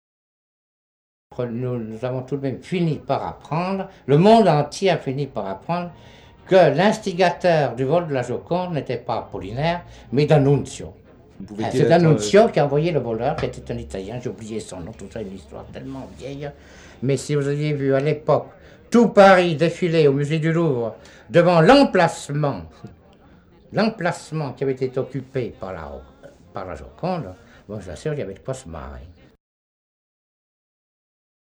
C'est une machine qui, d'une manière automatique diffuse ces bribes d'entretiens avec Blaise Cendrars. Comme une forme sonore distribuée entre nos téléphones mobiles, la brume acoustique cendrars est pulvérisée dans dans un espace élastique défini par les coordonnées spatiales de nos recepteurs téléphoniques.